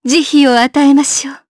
Isaiah-Vox_Skill6_jp.wav